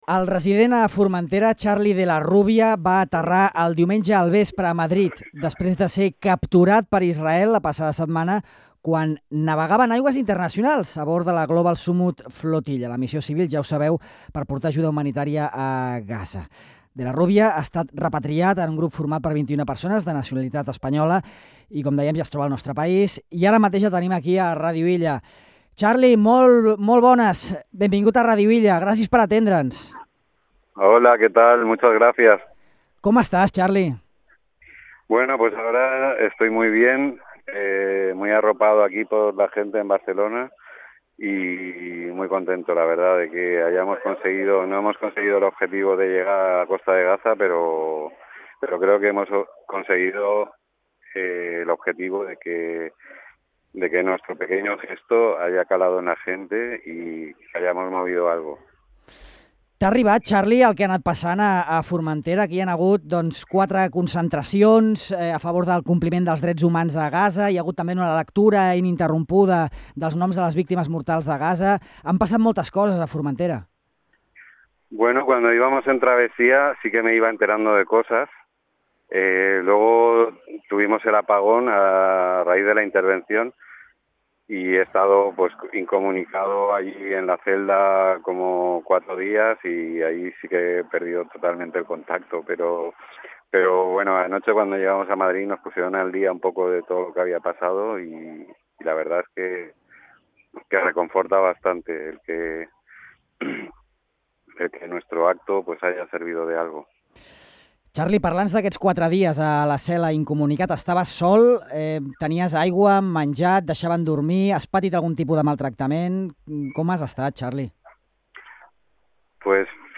En aquesta entrevista